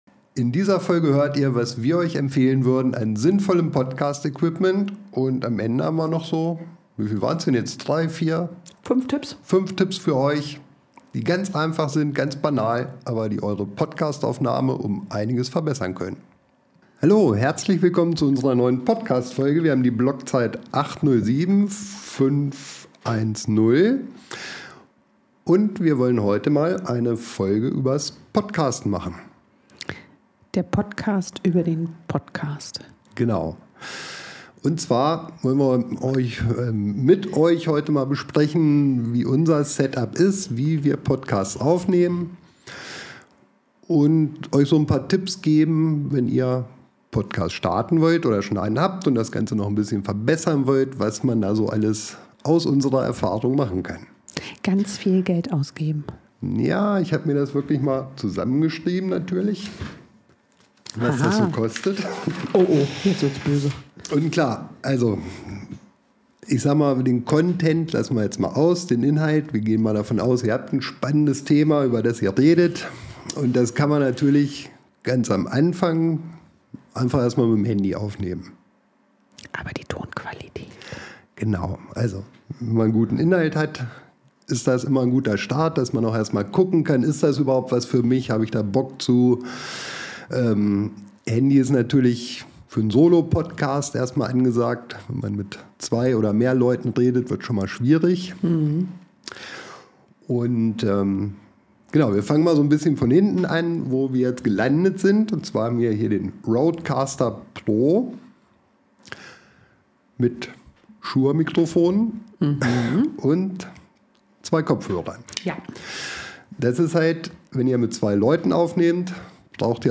Das perfekte Setup: Wir arbeiten aktuell mit dem „Rodecaster Pro2“, einem Audiointerface, das bis zu vier Mikrofone und Kopfhörer gleichzeitig unterstützt.
Wir nutzen Schallschutzmaterialien wie Vorhänge und Teppiche, um den Klang zu verbessern.